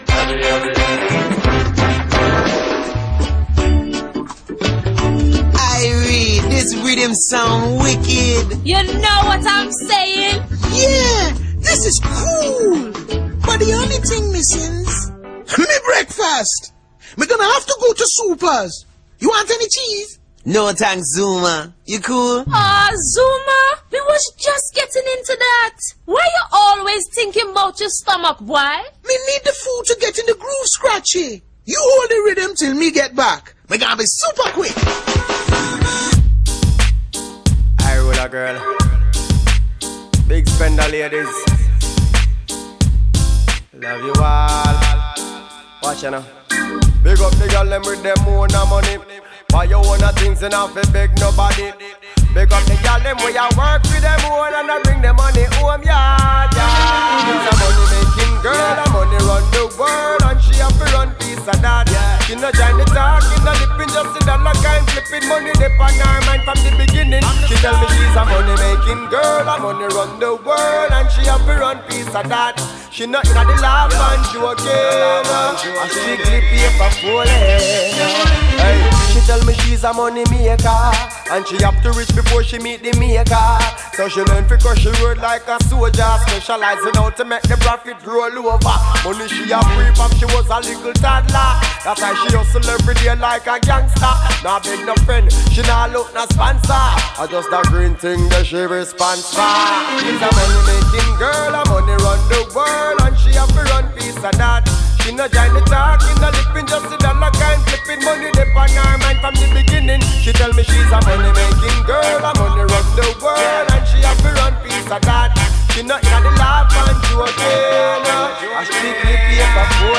reggae 45 selections